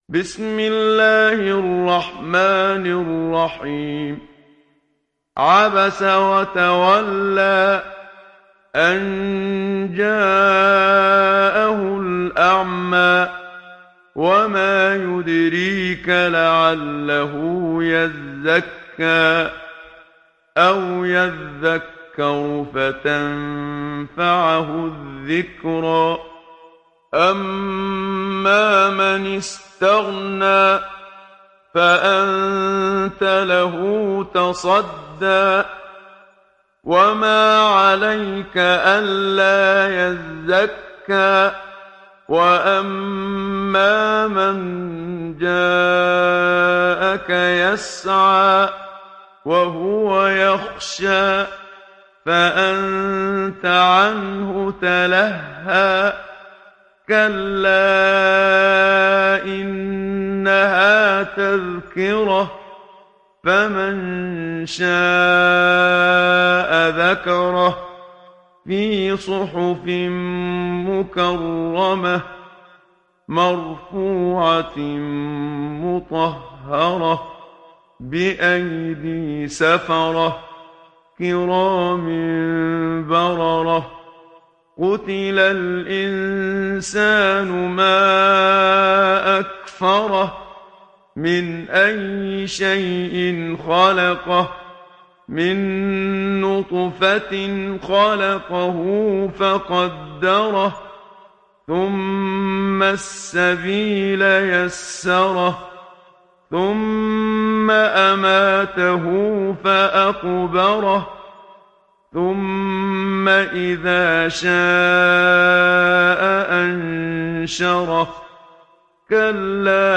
Sourate Abasa Télécharger mp3 Muhammad Siddiq Minshawi Riwayat Hafs an Assim, Téléchargez le Coran et écoutez les liens directs complets mp3